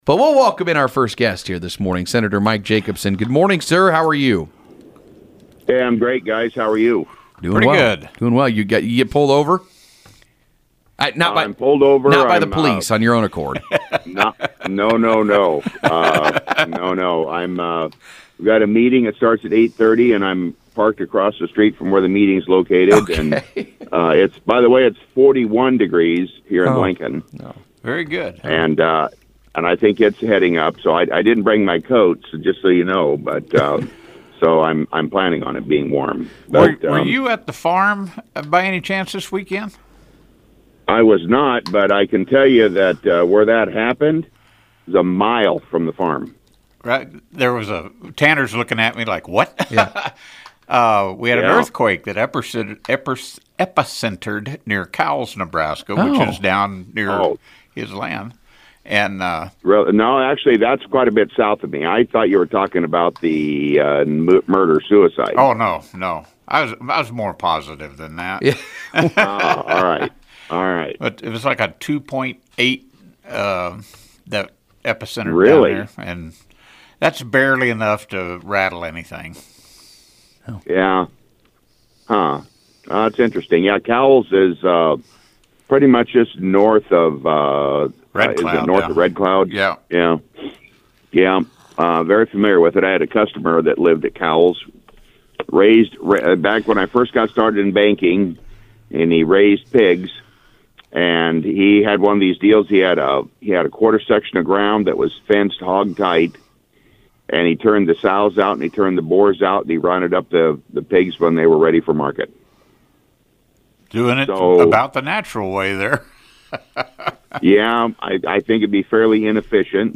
State Senator Mike Jacobson joined Huskeradio’s “Mugs in the Morning” on KODY this Monday, February 24th for his weekly appearance.
Topics included the daylight savings time bills that are currently being considered in the state legislature, along with his own bills that have made progress through the unicameral. Hear the full interview below.